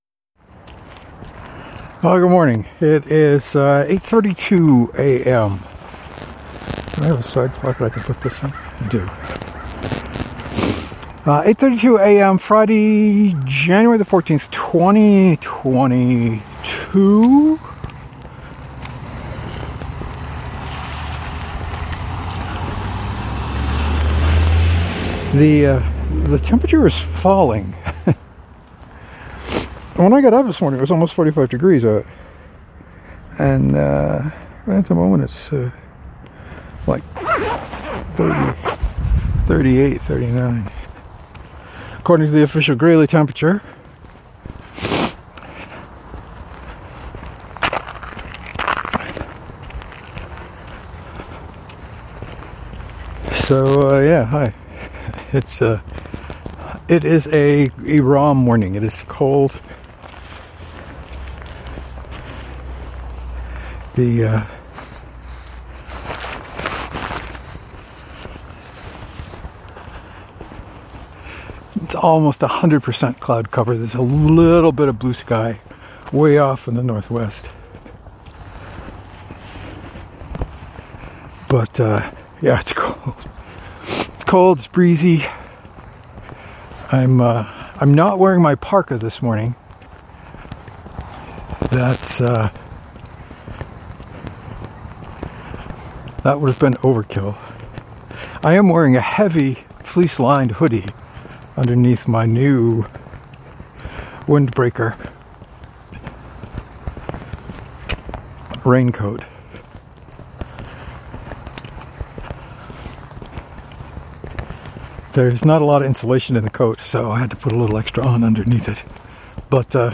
Production note: I forgot to turn on Airplane mode and the wind was vicious so the audio quality is a bit rough in places.